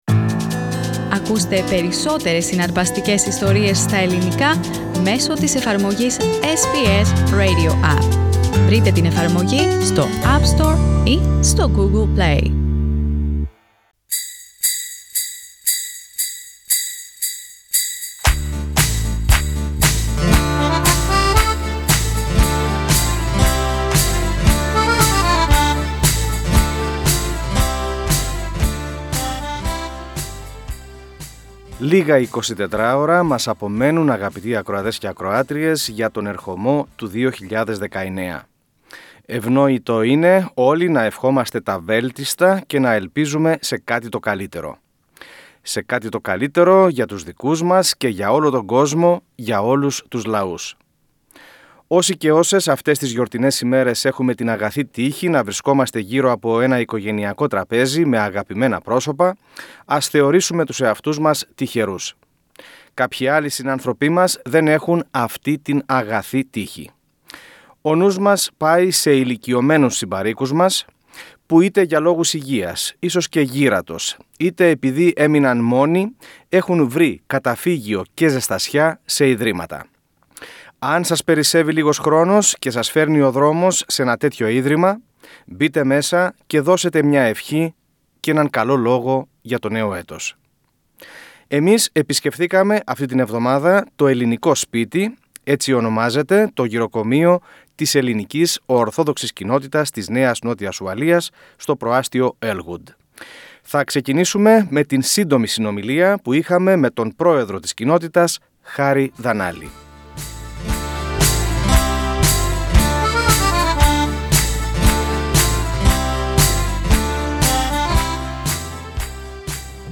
Εμείς επισκεφθήκαμε αυτή την εβδομάδα το «Ελληνικό Σπίτι», έτσι ονομάζεται το Γηροκομείο της Ελληνικής Ορθόδοξης Κοινότητας της ΝΝΟ, στο προάστειο Earlwood, του Σύδνεϋ.